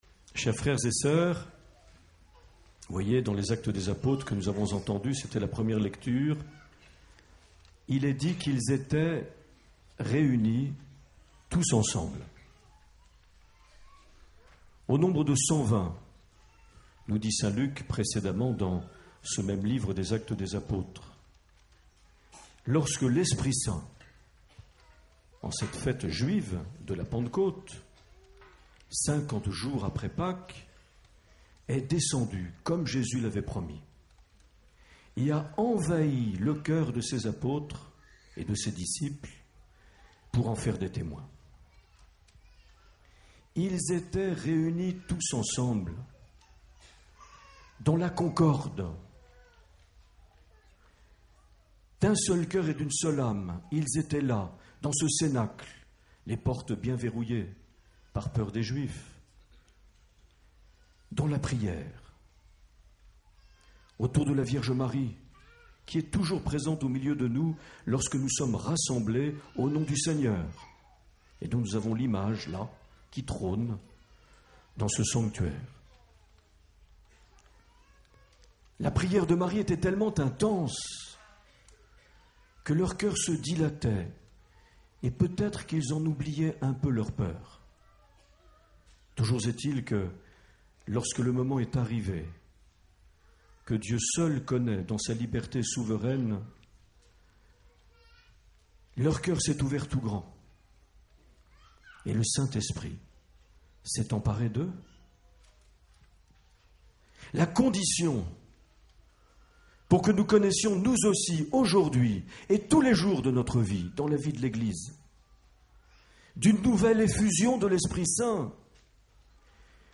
27 mai 2012 - Notre Dame du Refuge - Anglet - Messe de Pentecôte - Rassemblement diocésain "Famille en fête"
Accueil \ Emissions \ Vie de l’Eglise \ Evêque \ Les Homélies \ 27 mai 2012 - Notre Dame du Refuge - Anglet - Messe de Pentecôte - (...)
Une émission présentée par Monseigneur Marc Aillet